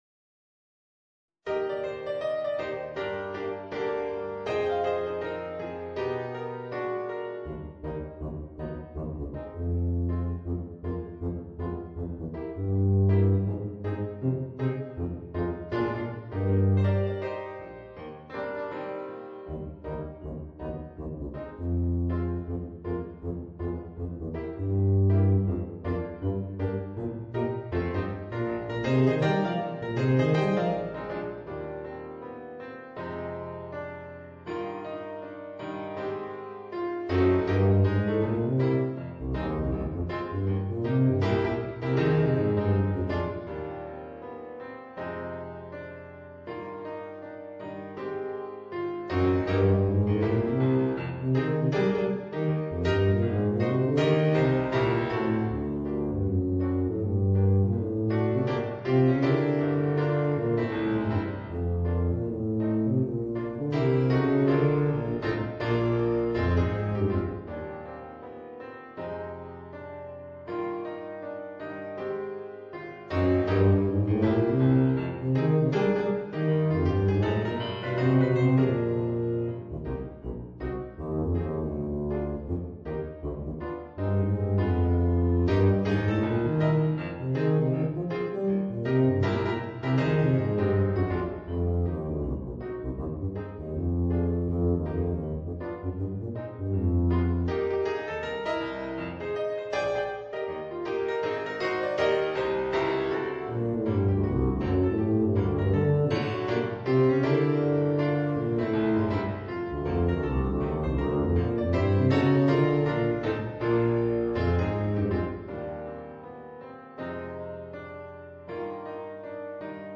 （テューバ+ピアノ）